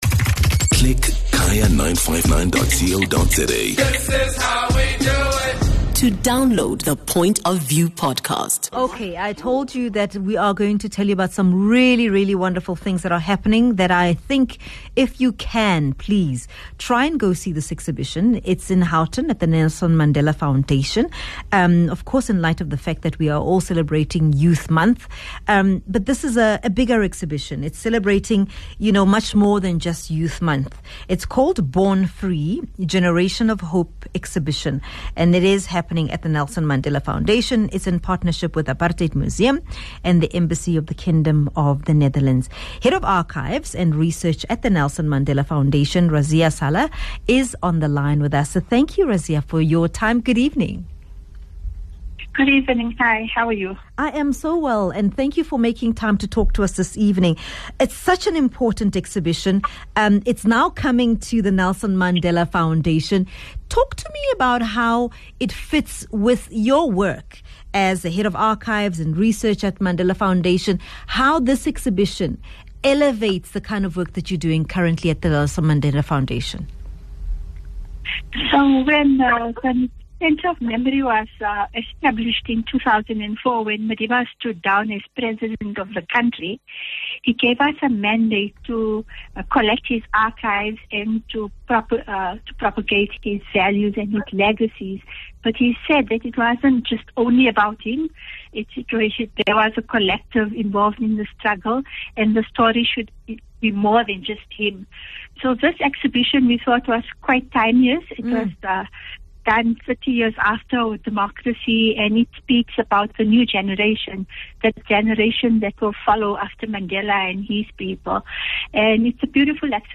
MUT Radio